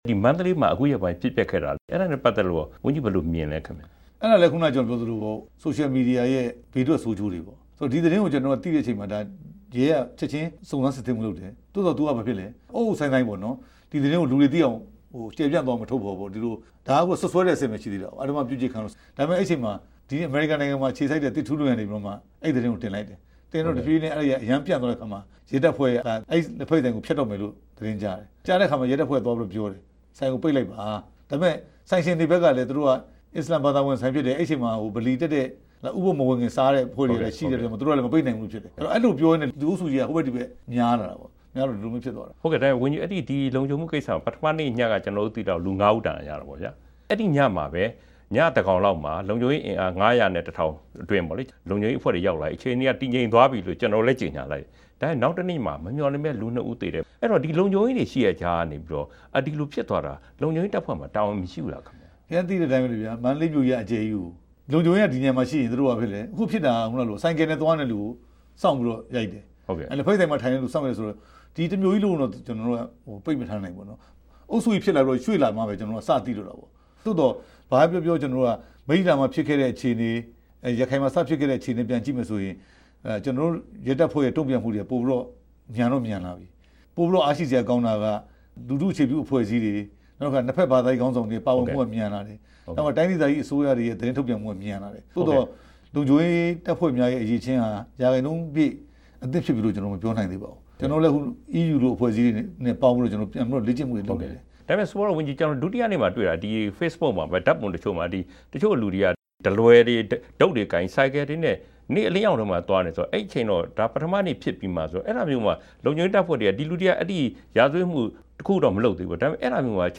ပြန်ကြားရေး ဒု ဝန်ကြီး ဦးရဲထွဋ်နဲ့ မေးမြန်းချက်